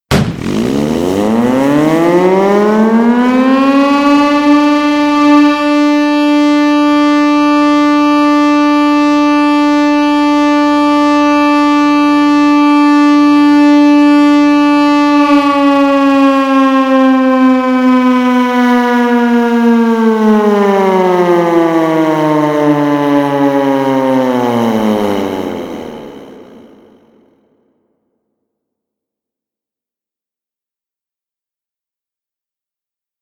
SHIP'S SIRENETTE
1 long blast for getting under way.
When activated, the sirenette makes a siren like whoop...whoop sound.
Since steam is not available as a historic site, the sirenette  is activated by a source of compressed air for demonstration purposes.
sirenette long blast.mp3